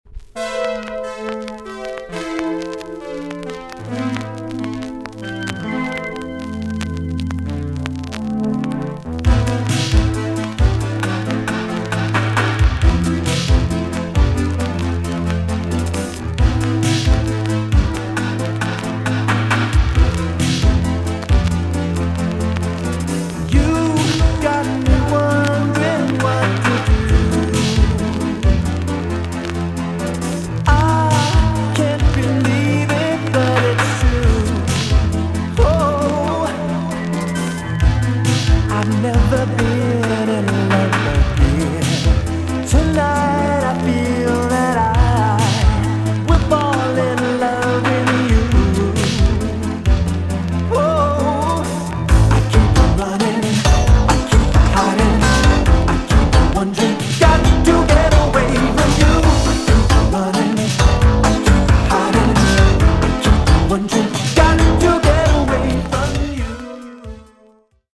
VG+ 少し軽いチリノイズが入ります。